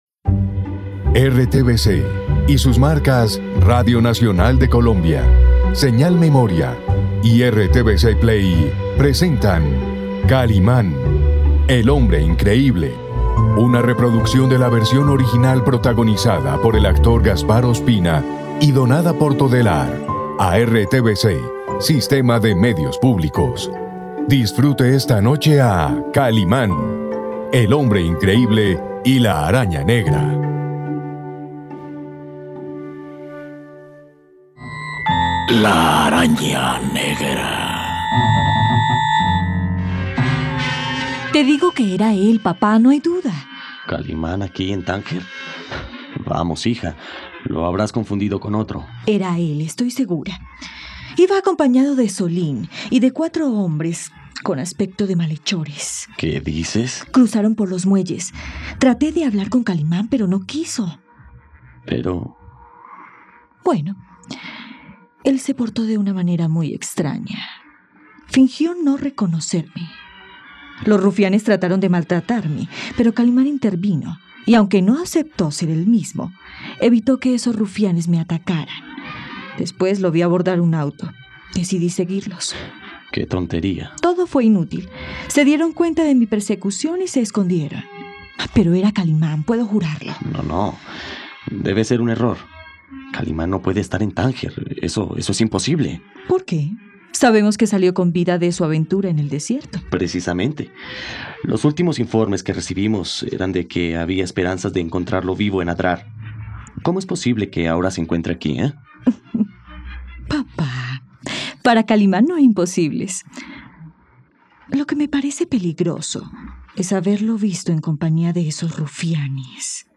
Podcast narrativo.
radionovela